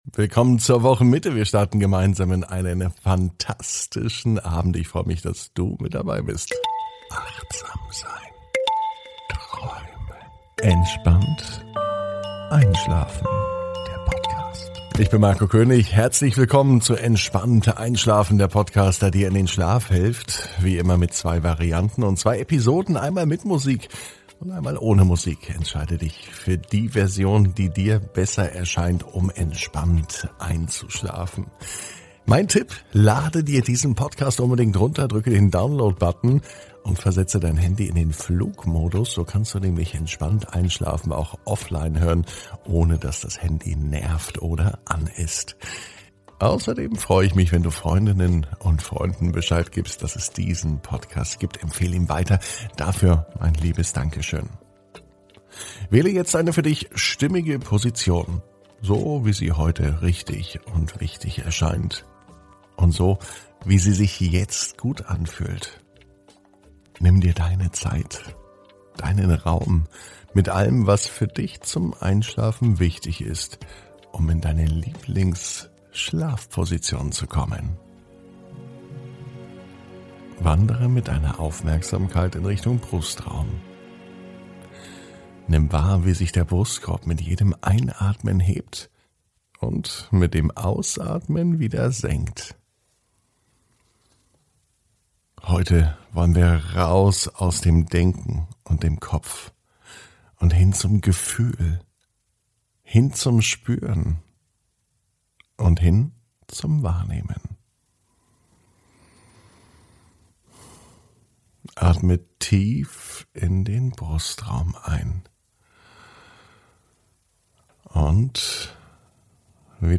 (ohne Musik) Entspannt einschlafen am Mittwoch, 19.05.21 ~ Entspannt einschlafen - Meditation & Achtsamkeit für die Nacht Podcast